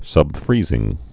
(sŭb-frēzĭng)